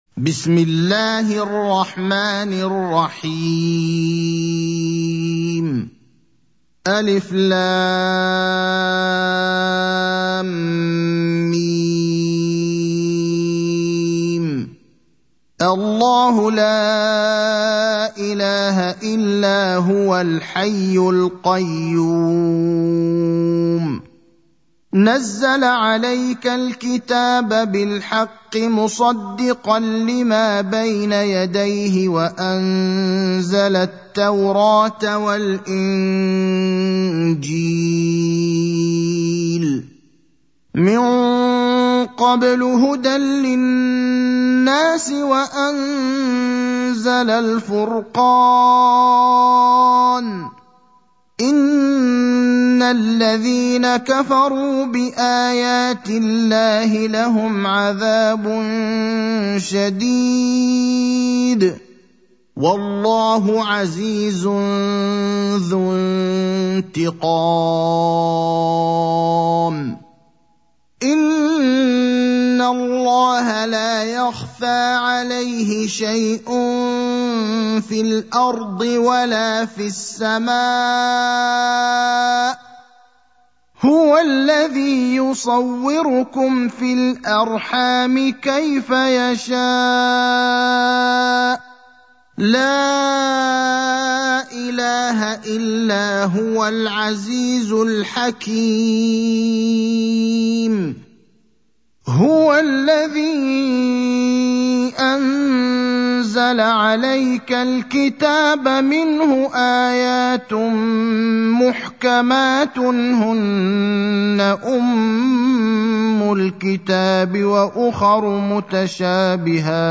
المكان: المسجد النبوي الشيخ: فضيلة الشيخ إبراهيم الأخضر فضيلة الشيخ إبراهيم الأخضر سورة آل عمران